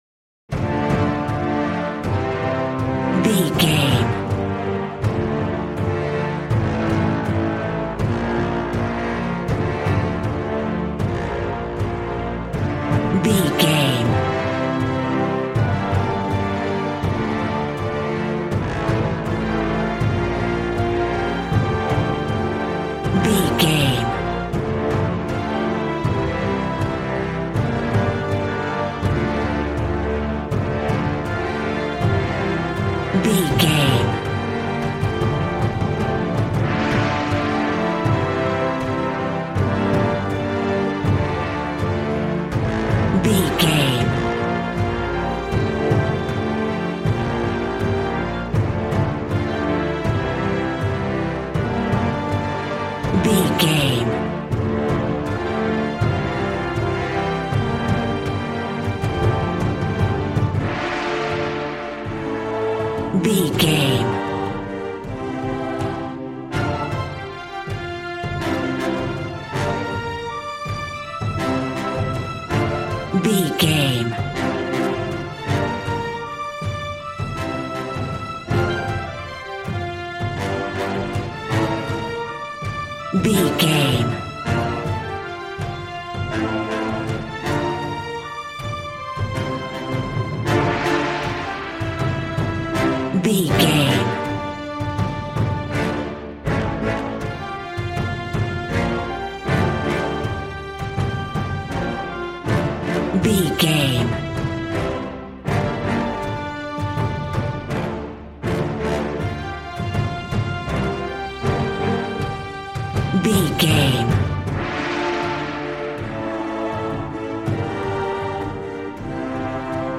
Regal and romantic, a classy piece of classical music.
Aeolian/Minor
D
regal
cello
double bass